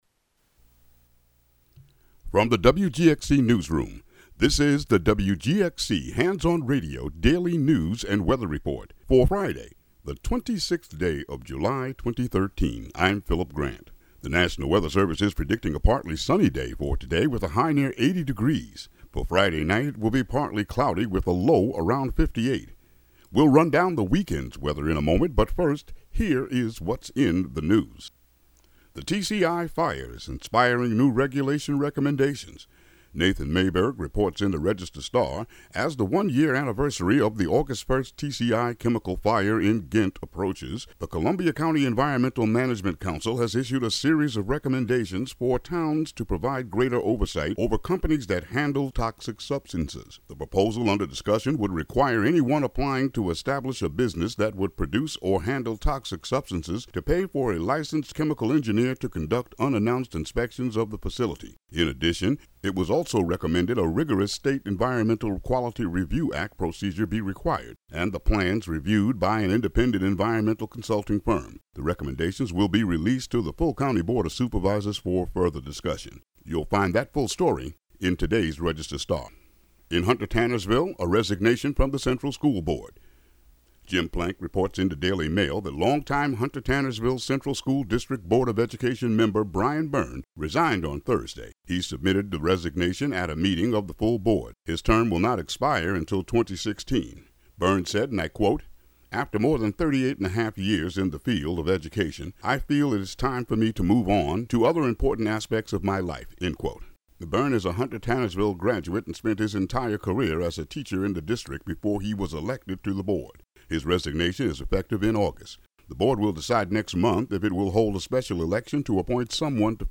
Local news and weather for Friday, July 26, 2013.